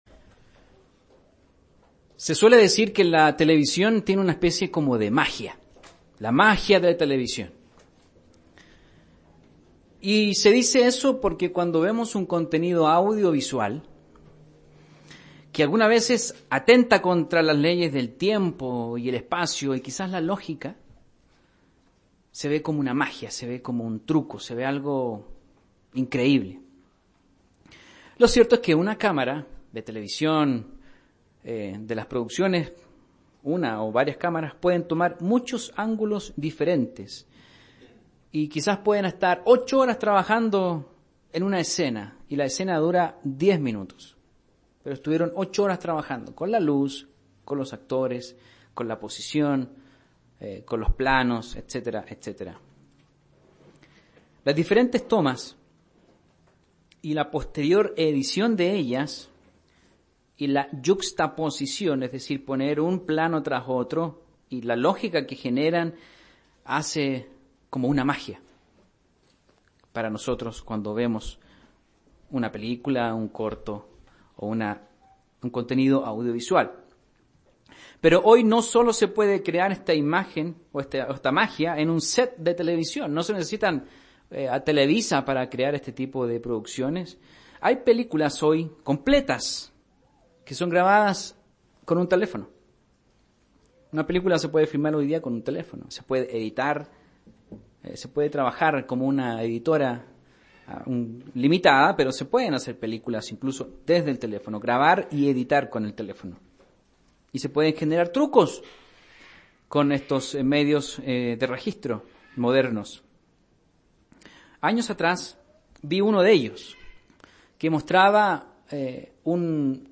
El carácter del apóstol Pedro, pasó de ser uno emocional a uno espiritual gracias al cambio de perspectiva propiciado por el espíritu santo de Dios. Mensaje entregado el 13 de enero de 2018